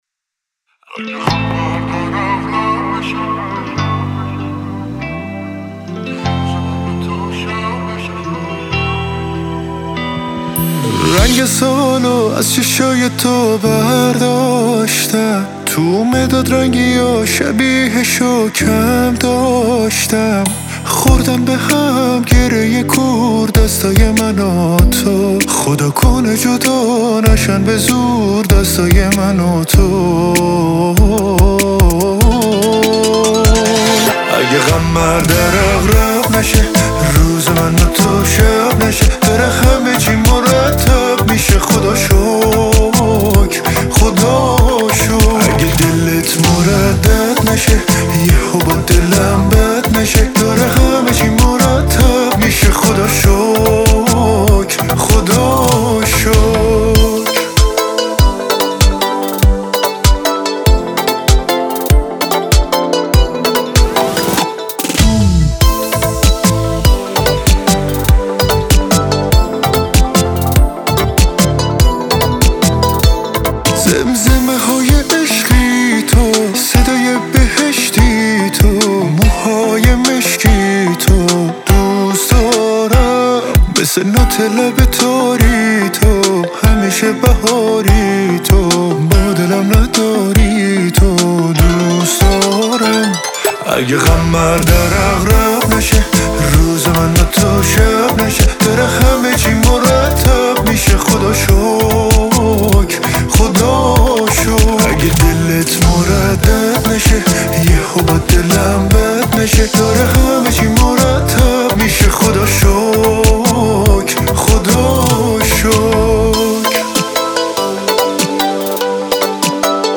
• آهنگ شاد